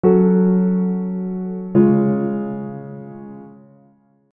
Half Cadence F#m - C#
The half cadence resolves to the dominant chord which lacks the definitive sense of closure provided by the tonic.